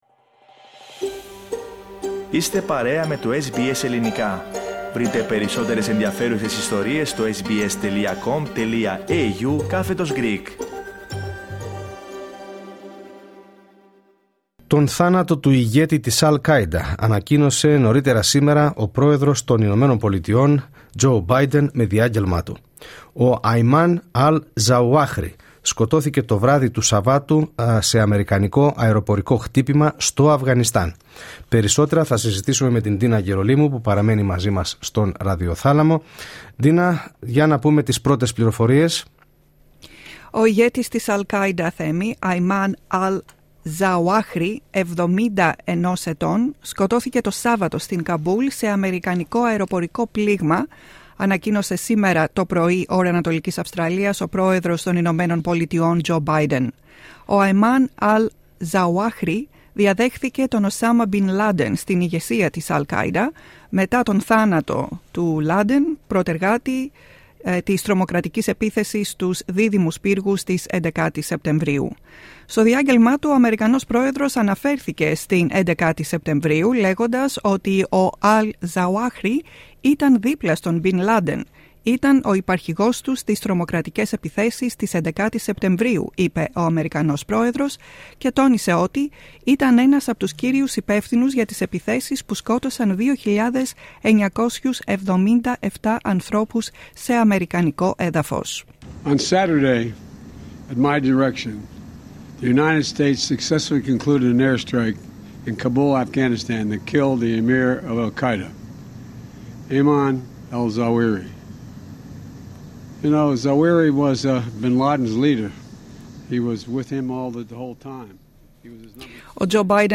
US President Joe Biden speaks on a "successful" counter-terrorism operation that killed al-Qaeda chief Ayman al-Zawahiri.